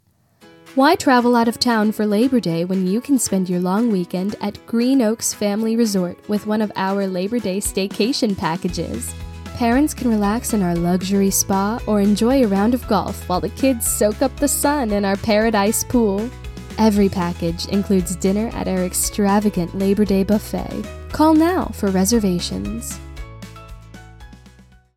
Demos
Green Oaks Resort, Eloquent:Friendly:Personable
Southern (American)
My timbre is youthful and bright with a clear and direct expression.